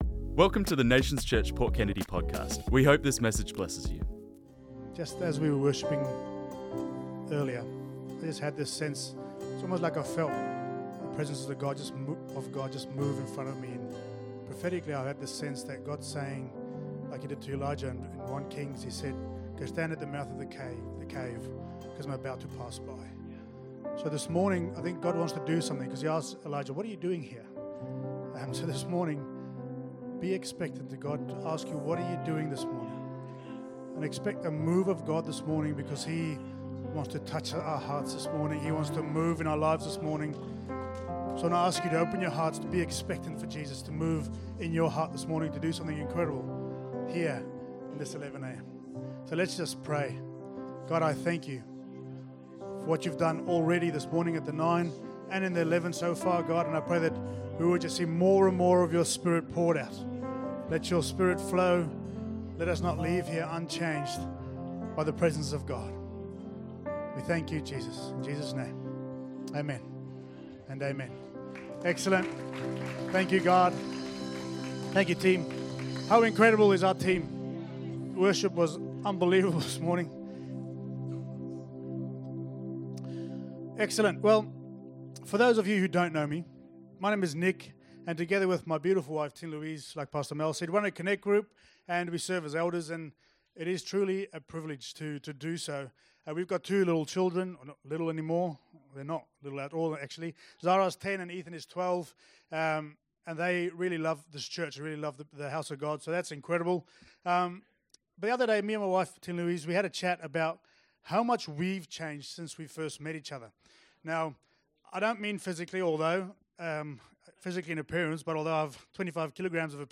This message was preached on Sunday the 26th October 2025